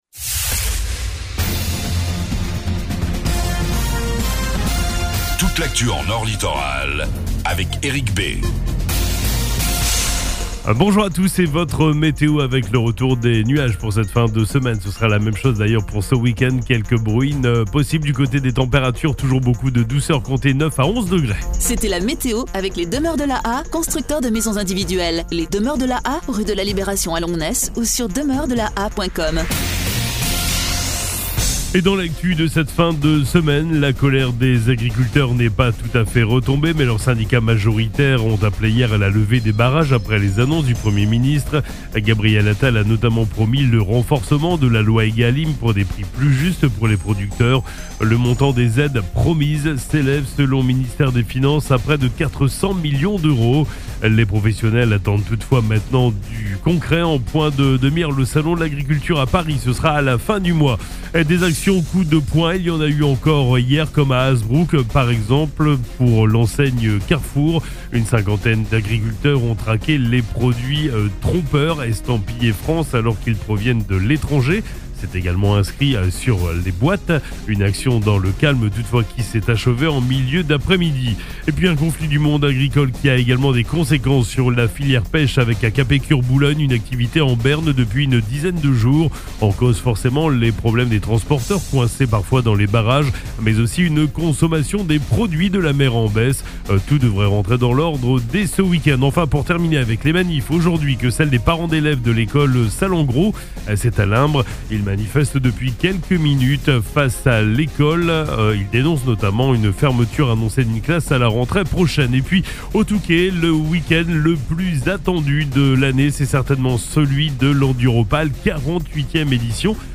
FLASH 02 FEVRIER 24